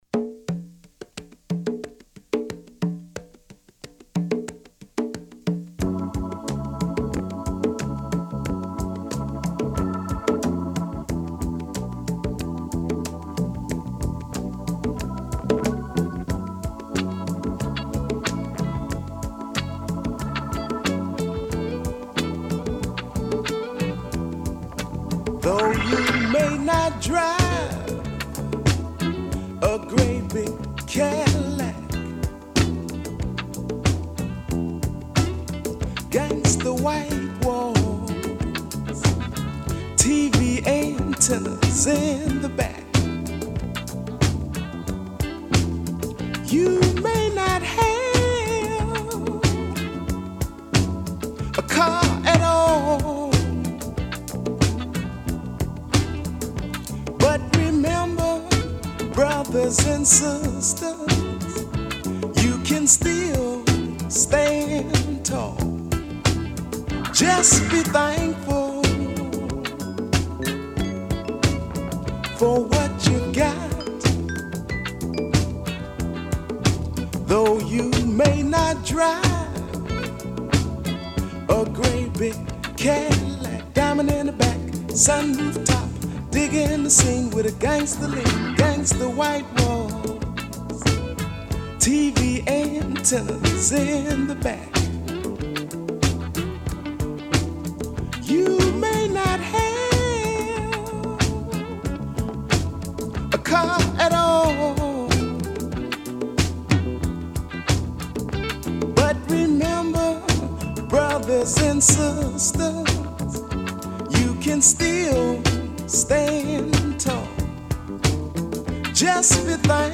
His song oozes positivity with a ridiculous level of cool.
Easy, breezy